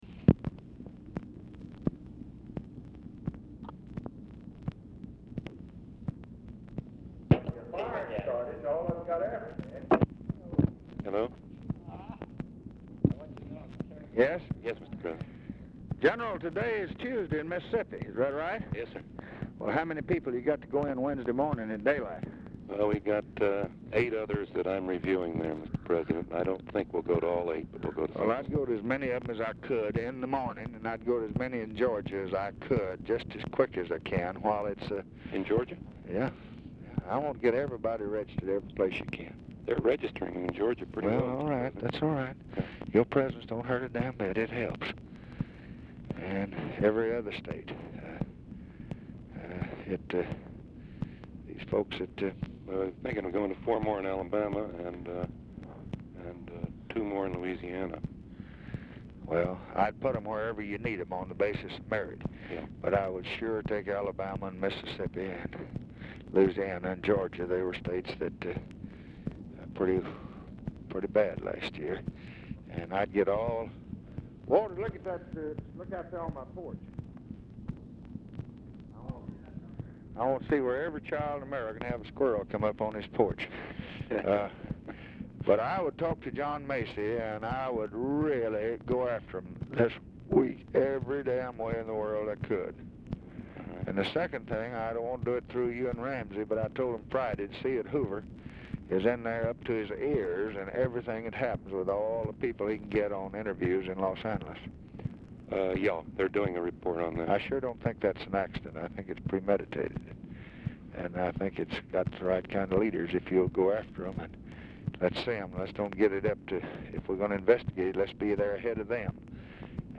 Telephone conversation # 8544, sound recording, LBJ and NICHOLAS KATZENBACH, 8/17/1965, 11:55AM | Discover LBJ
BRIEF OFFICE CONVERSATION BEFORE AND DURING CALL
Format Dictation belt
Location Of Speaker 1 Oval Office or unknown location